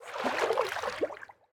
sounds / mob / dolphin / swim1.ogg
swim1.ogg